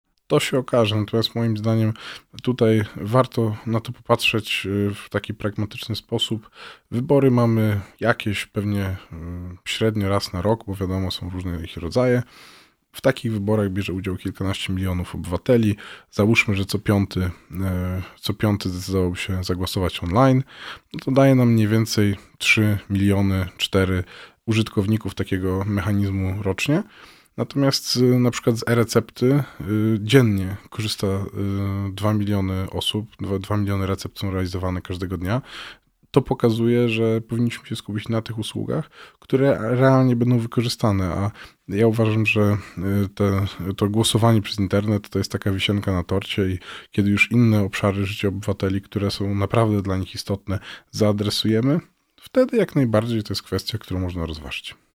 Kiedy będziemy mogli głosować przez aplikację, profil zaufany?  Odpowiada Janusz Cieszyński – sekretarz stanu w KPRM, pełnomocnik rządu do spraw cyberbezpieczeństwa.